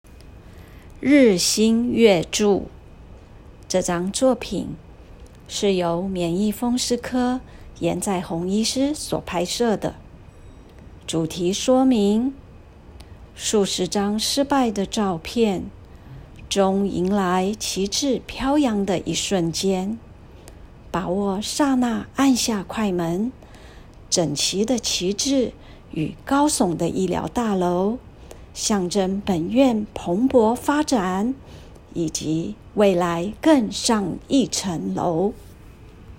語音導覽，另開新視窗
語音導覽-45日新月著.m4a